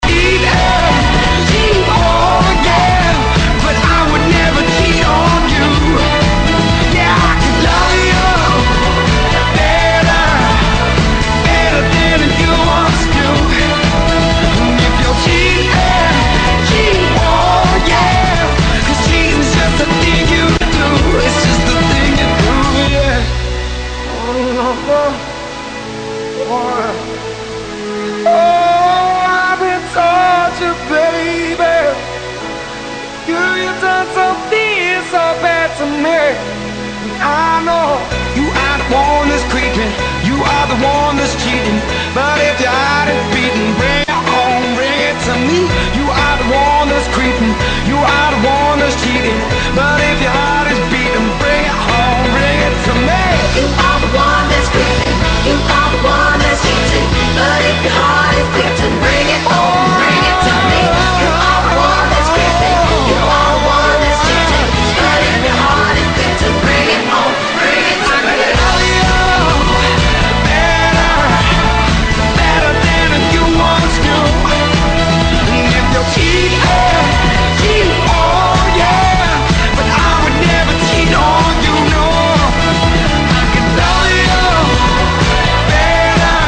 Как по мне, так отлично! Не хуже чем FM !
как по мне,очень много низких частот.